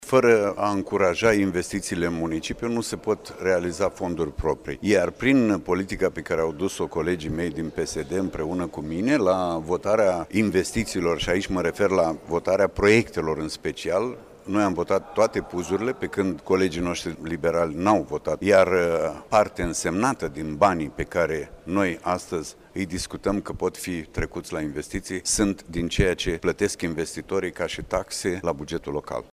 După 4 ore de dezbateri, bugetul municipiului Iaşi a fost aprobat, astăzi, în şedinţa Consiliului Local.
Preşedintele Grupului Social Democrat din Consiliul Local, Gabriel Surdu, a subliniat faptul că bugetul a fost dezbătut în Comisii, iar pe viitor ar trebui pus un accent mai mare pe investitorii locali.